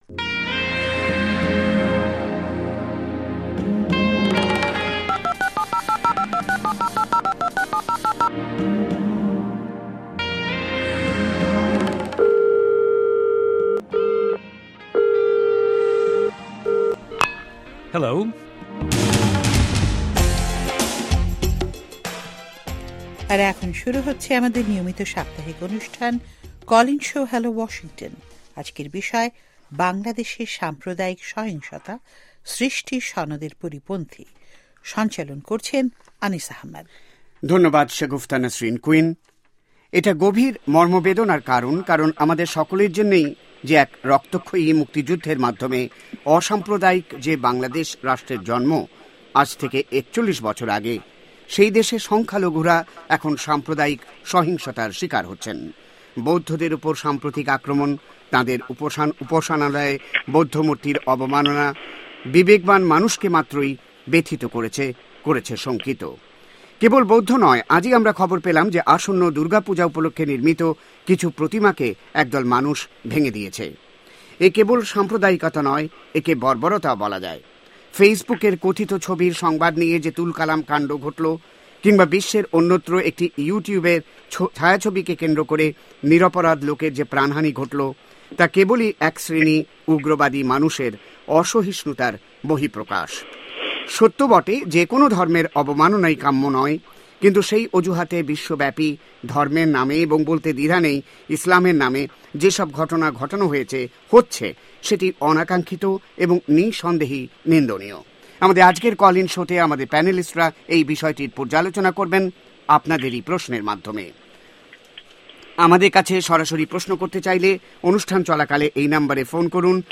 এ সপ্তার কল ইন শো